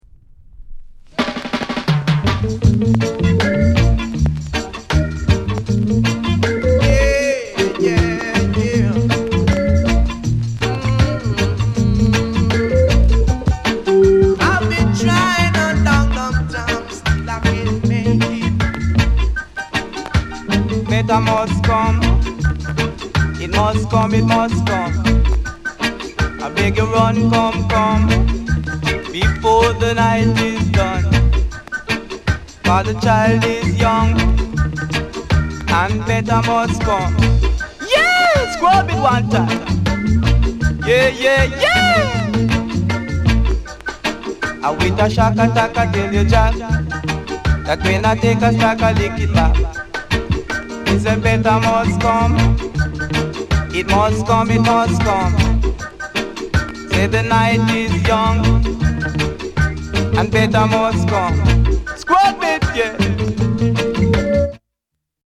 NICE ROCKSTEADY